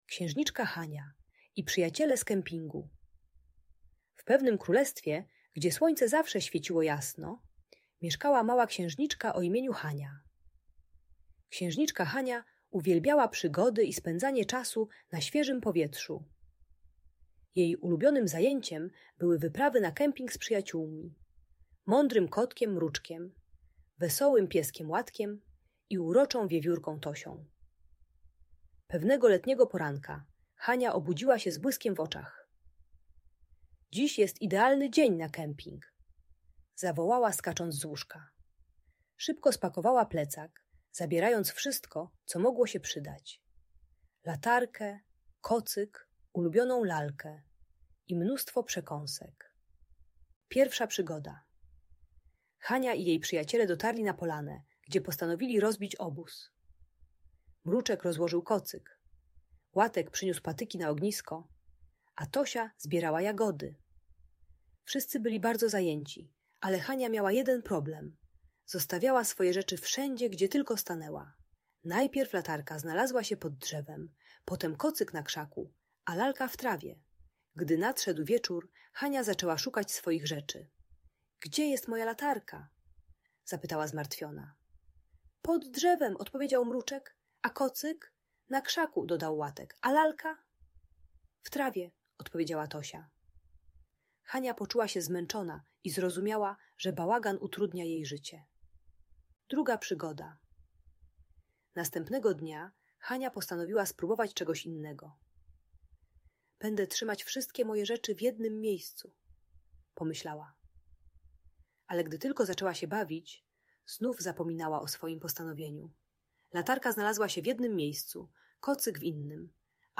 Historia Księżniczki Hani i Przyjaciół - Niepokojące zachowania | Audiobajka